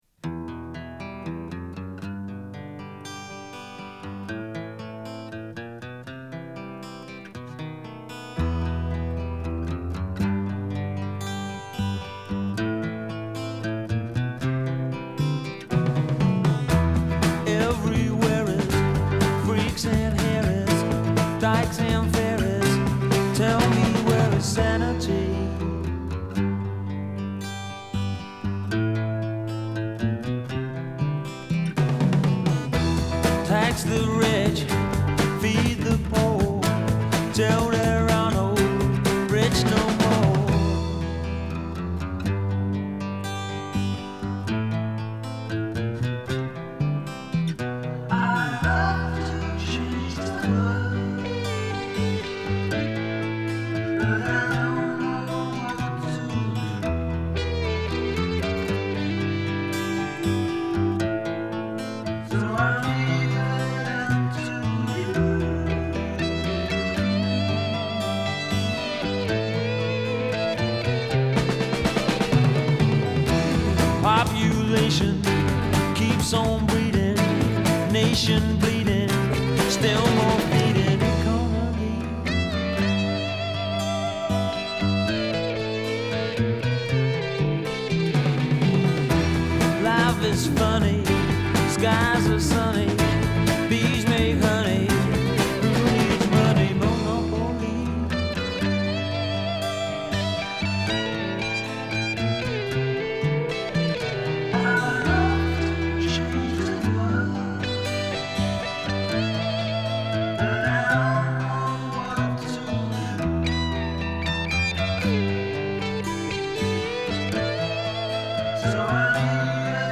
рок-музыка